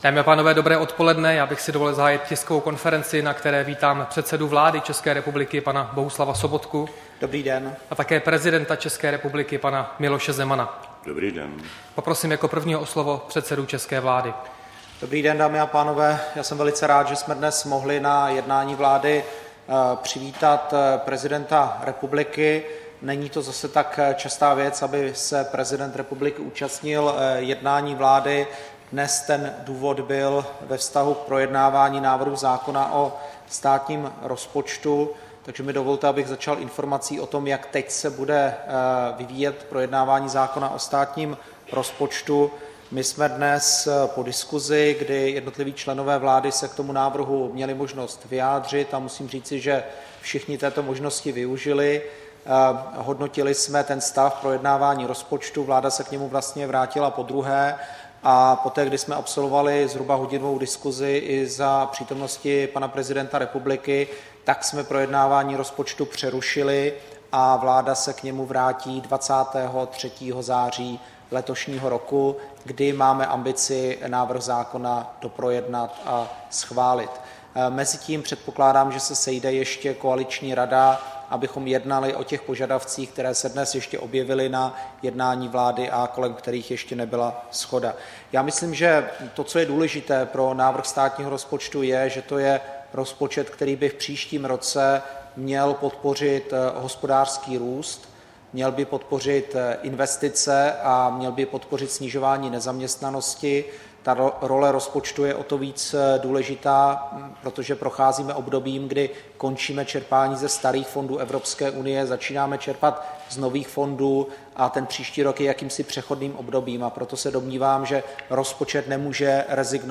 Tisková konference předsedy vlády Bohuslava Sobotky a prezidenta Miloše Zemana po jednání vlády, 9. září 2015